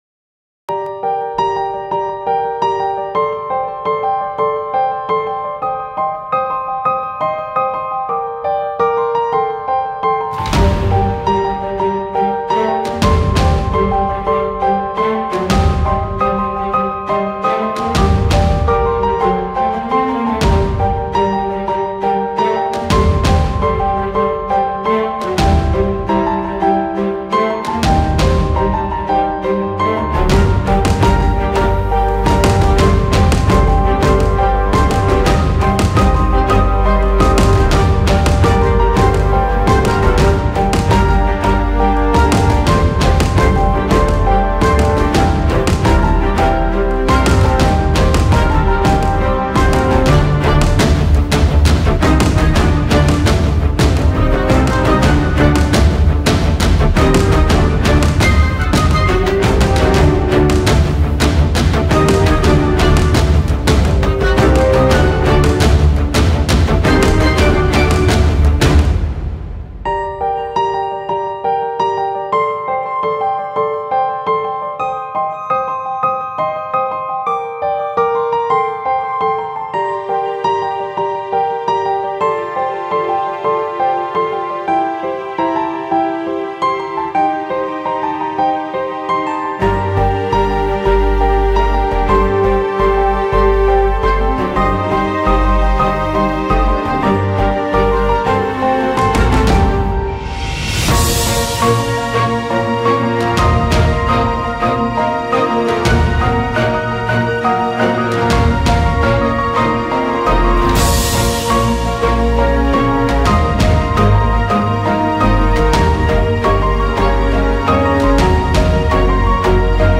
FIRE-DANCE-Powerful-HQ-Emotional-Piano-Epic-Music-_-Inspirational-Instrumental-Music.mp3